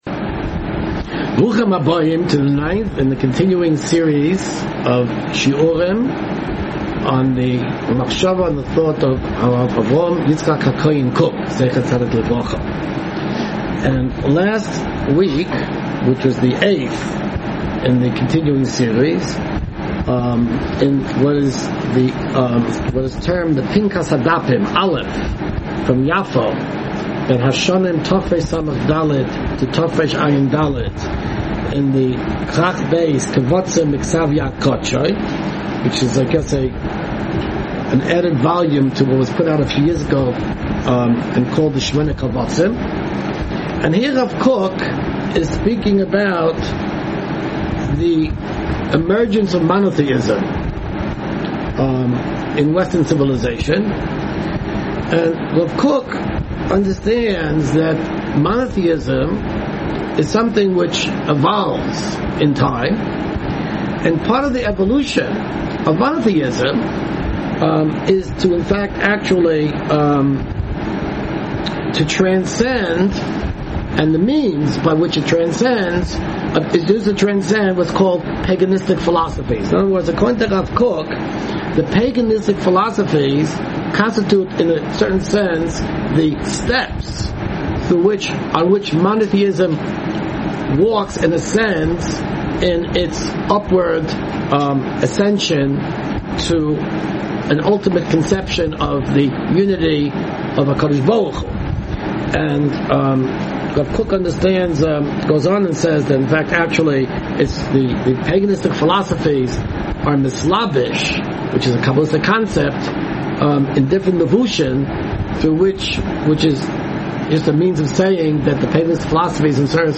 Rav Kook Pinkas HaDapim shiur 9